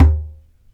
DJEMBE 5A.WAV